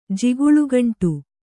♪ jiguḷu gaṇṭu